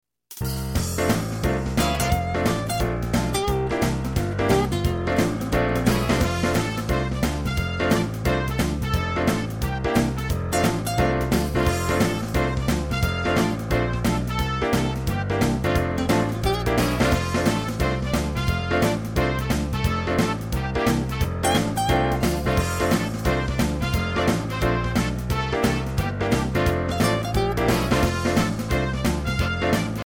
instrumental clip